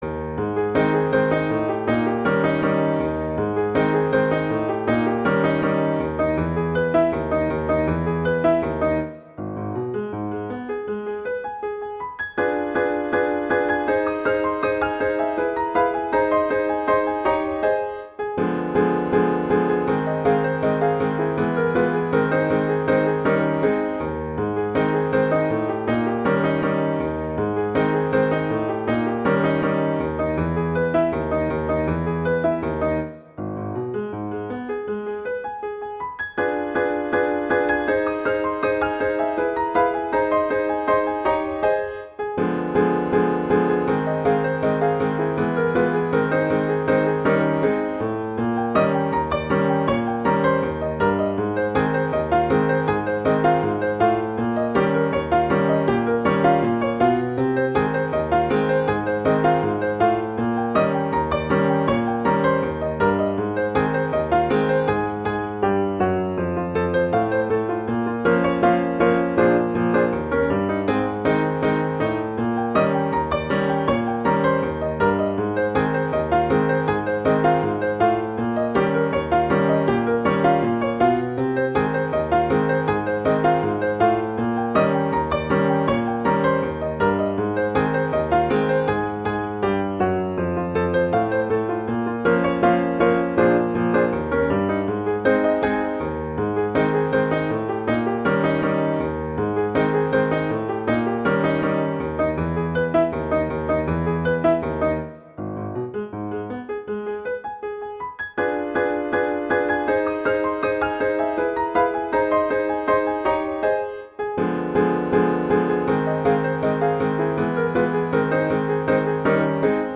jazz, classical
Ab major
♩=80 BPM